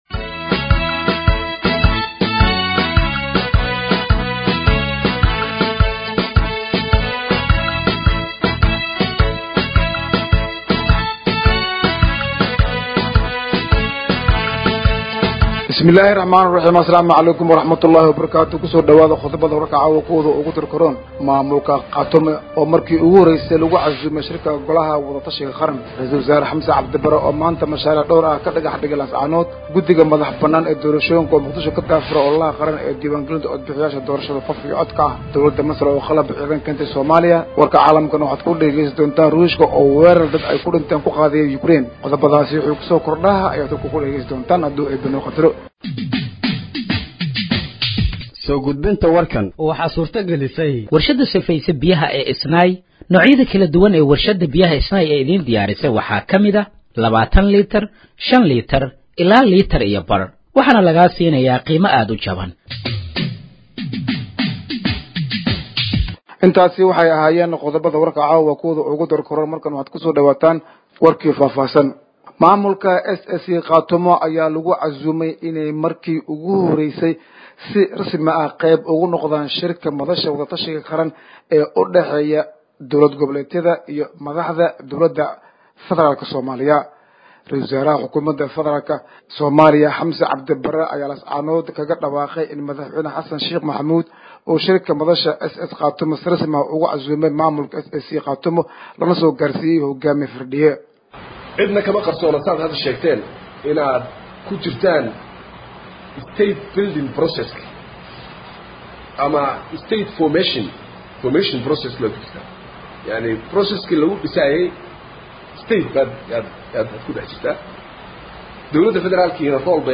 Dhageeyso Warka Habeenimo ee Radiojowhar 13/04/2025